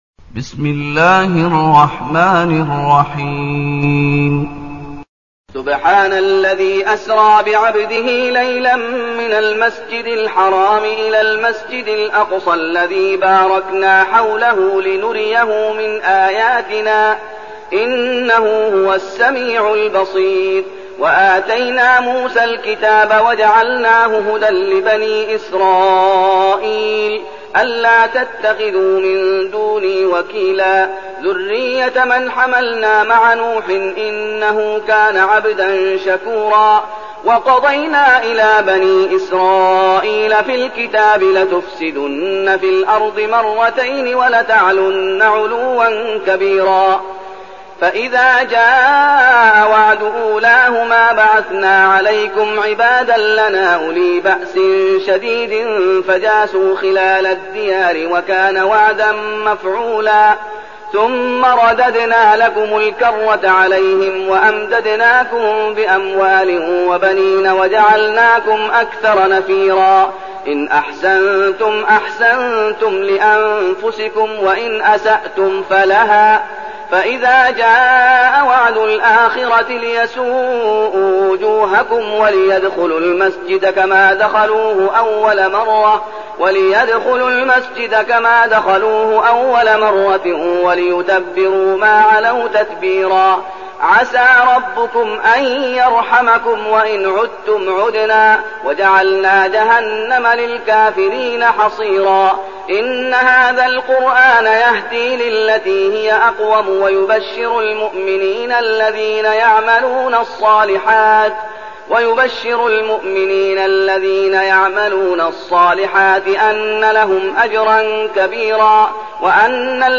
المكان: المسجد النبوي الشيخ: فضيلة الشيخ محمد أيوب فضيلة الشيخ محمد أيوب الإسراء